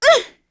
Shamilla-Vox_Damage_kr_01.wav